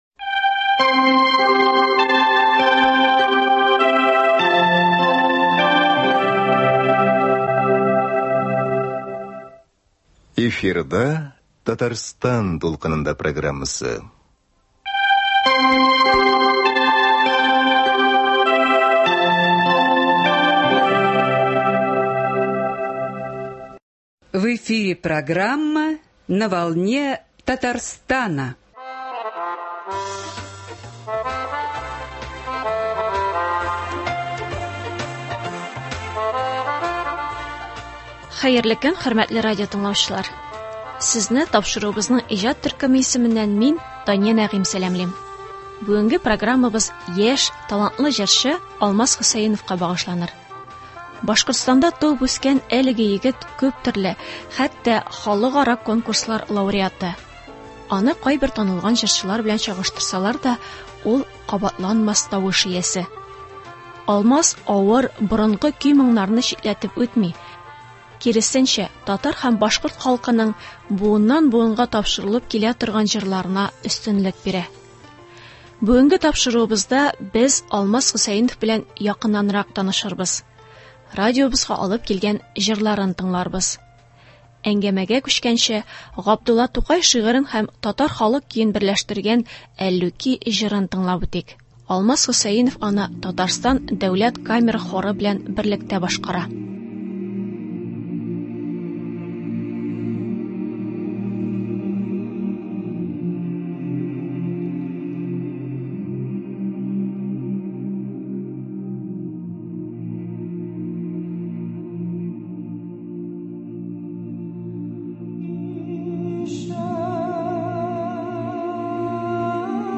Аны кайбер танылган җырчылар белән чагыштырсалар да, ул кабатланмас тавыш иясе.